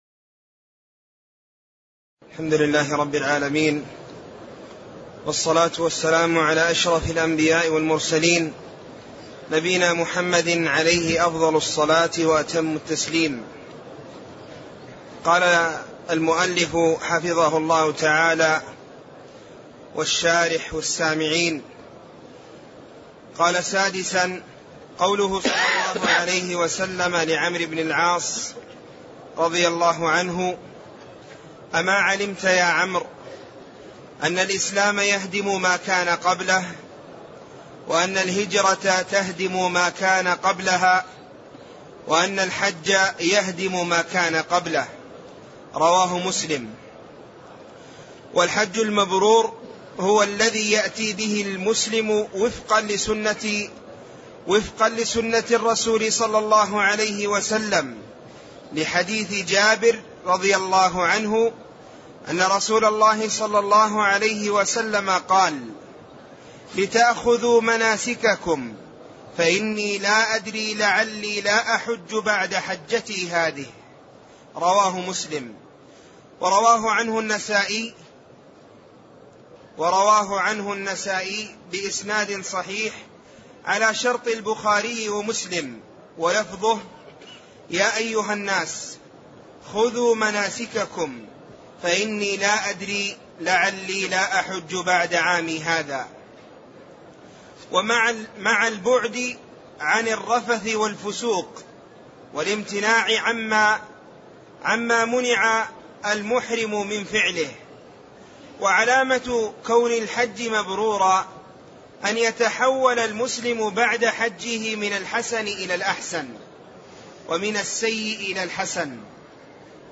تاريخ النشر ٢٢ ذو القعدة ١٤٣٠ المكان: المسجد النبوي الشيخ